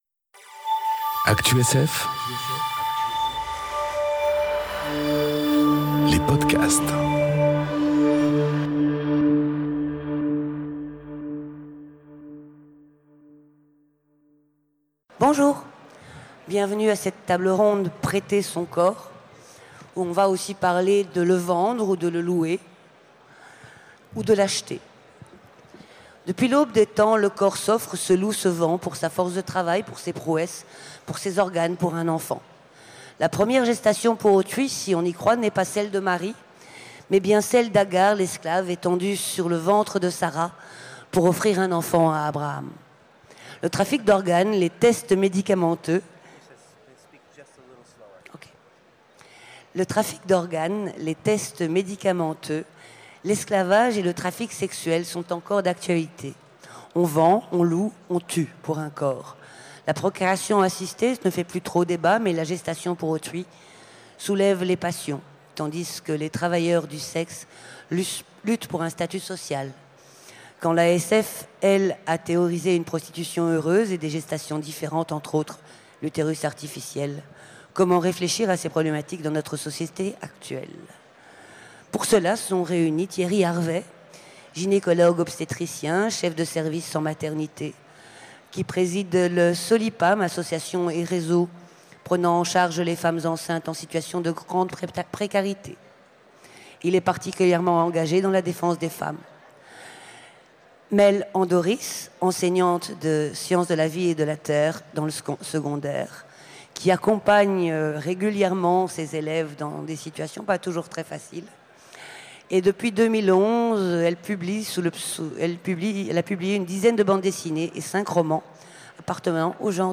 Conférence Prêter son corps enregistrée aux Utopiales 2018